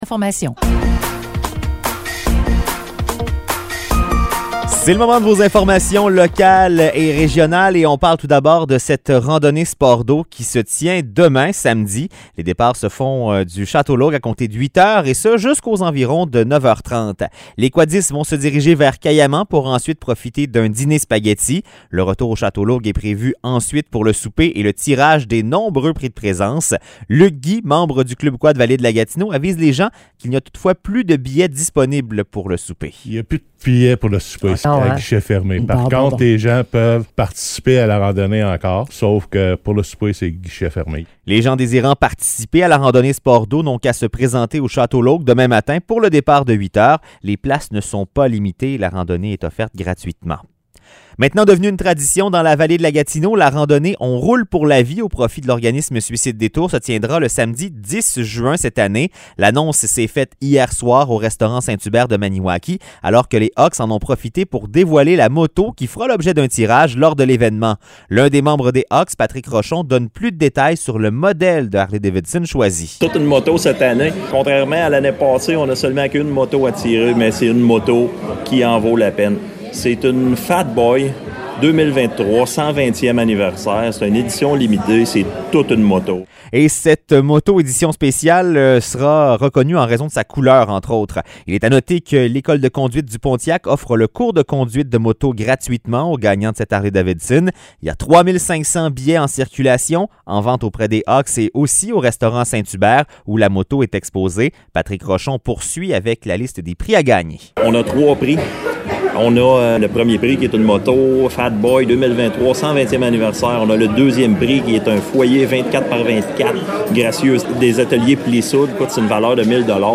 Nouvelles locales - 3 mars 2023 - 16 h